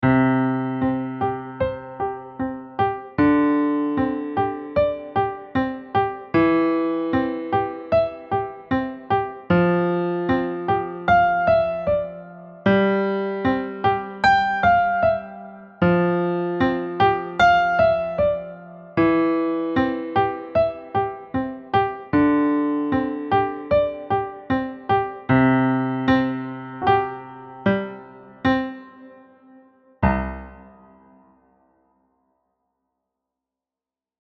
high-energy elementary piano solo
Key: C Major
Time Signature: 4/4
Character: Energetic, rhythmic, playful
• Developing a steady beat with consistent rhythmic motion